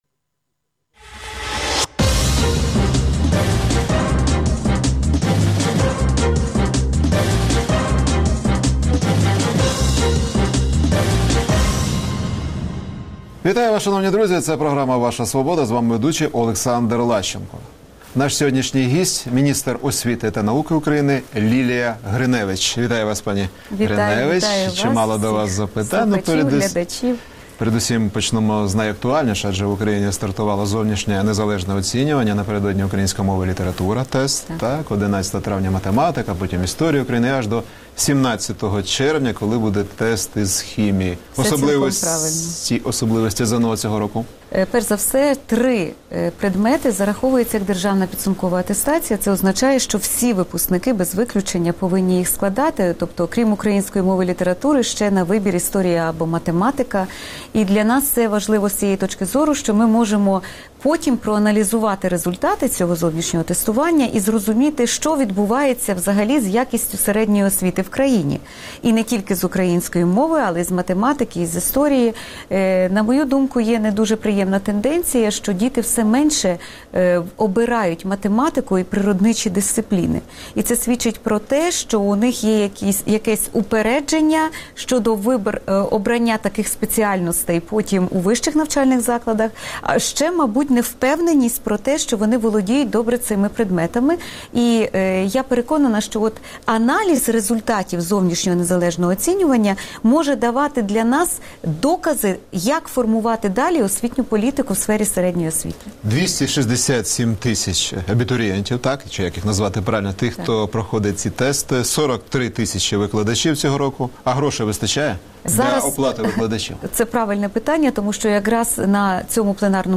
Міністр освіти Лілія Гриневич розповіла про ЗНО та 12-річну шкільну освіту